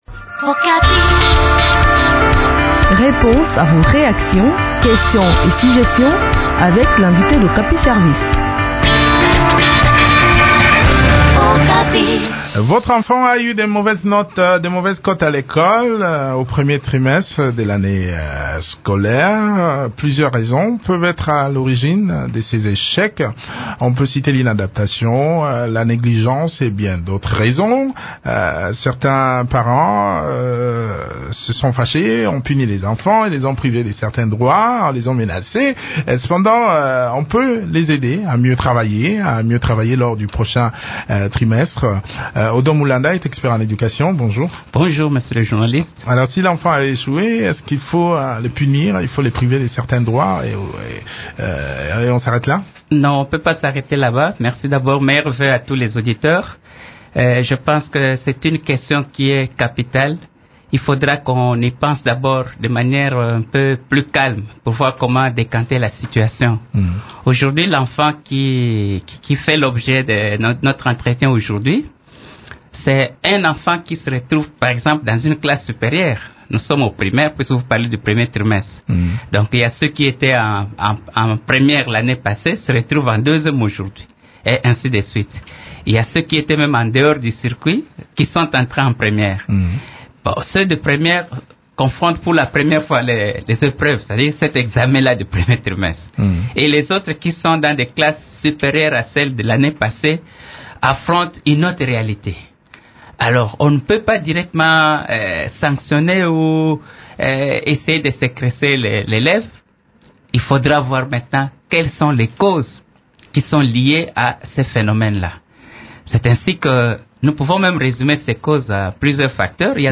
spécialiste en Sciences de l’éducation.